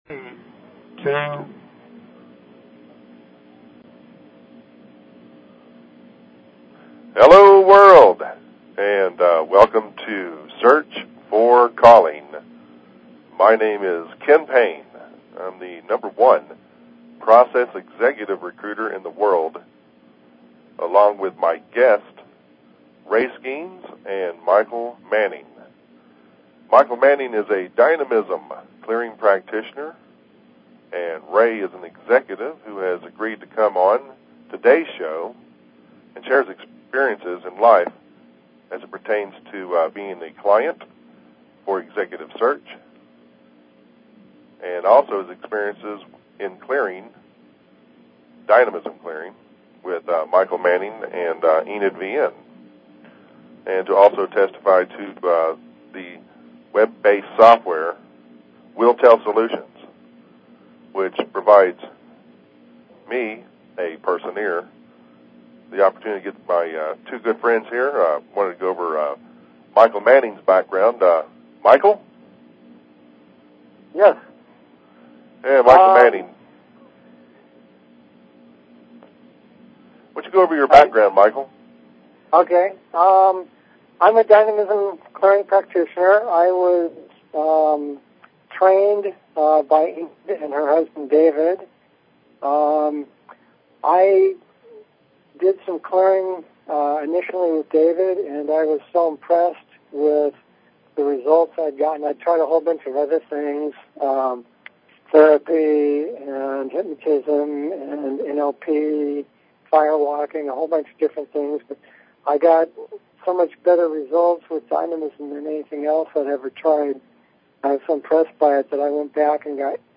Talk Show Episode, Audio Podcast, Search_for_Calling and Courtesy of BBS Radio on , show guests , about , categorized as